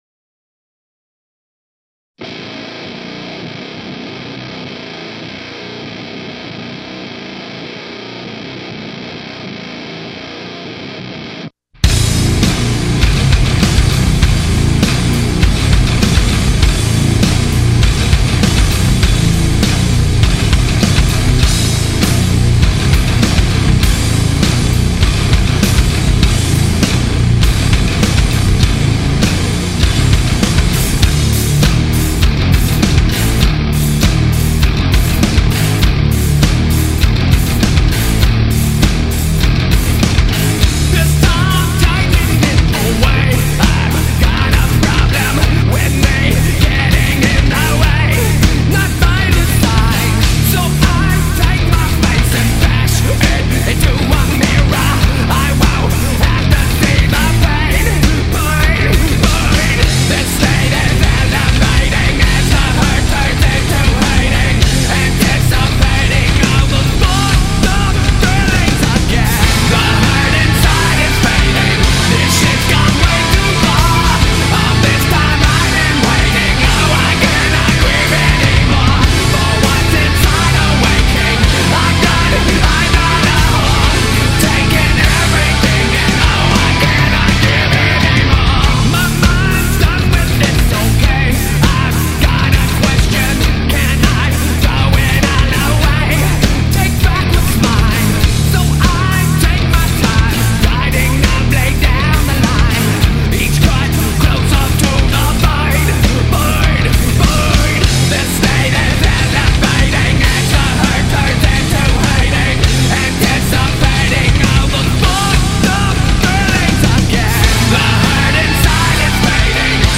La Zona con todo sobre este grupo Nu-Aggro Metal